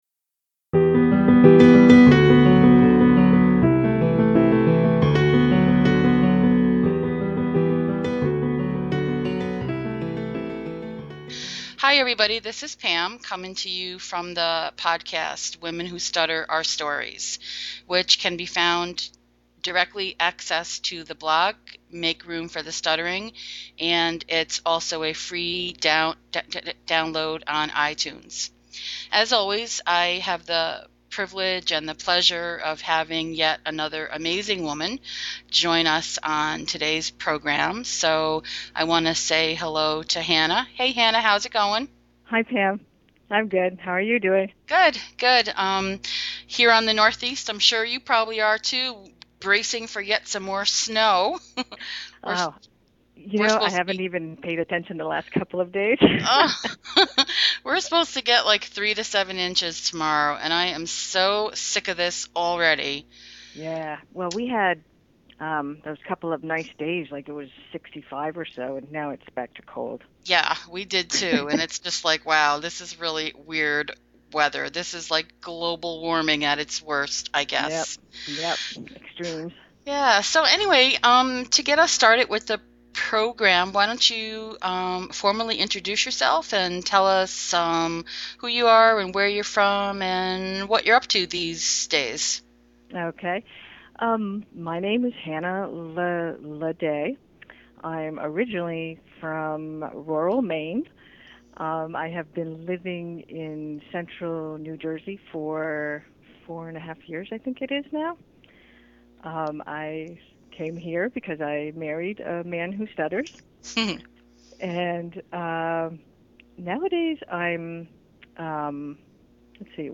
Listen in to a robust dialogue with plenty of emotion. We talk about how and why we limit ourselves, being in groups but not really part of them, courage and change.